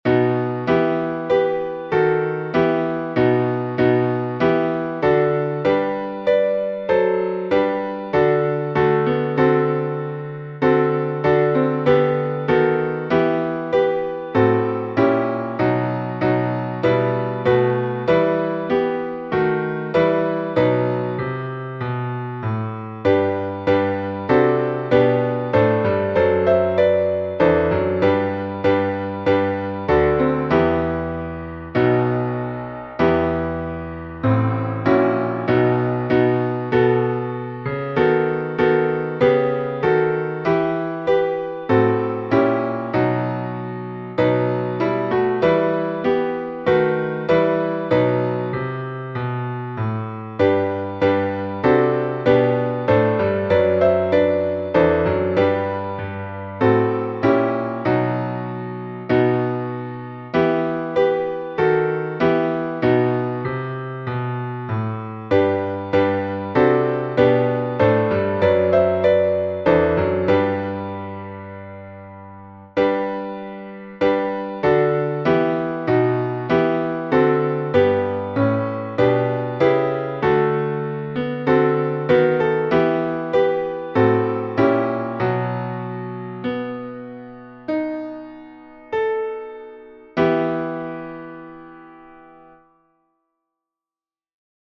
Киевский распев